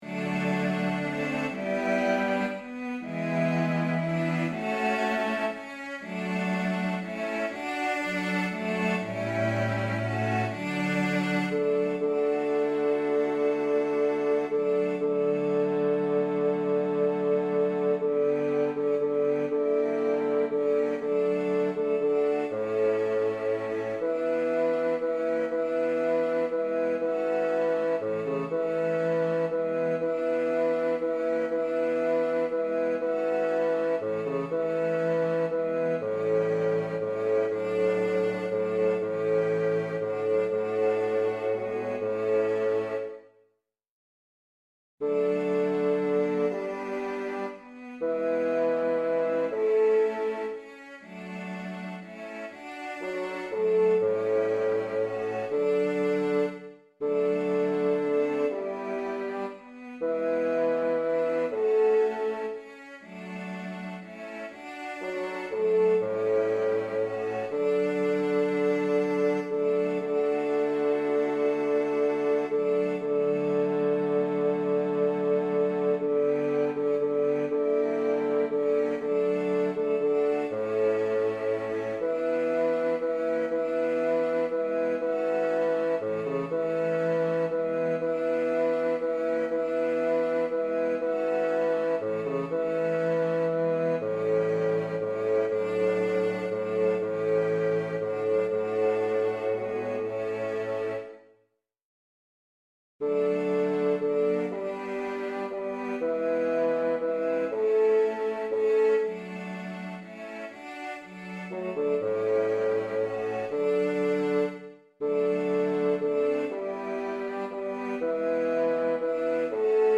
Wie schön bist du (Die Nacht) 3 Strophen Bass 2 als Mp3
wie-schoen-bist-du-die-nacht-3-strophen-einstudierung-bass-2.mp3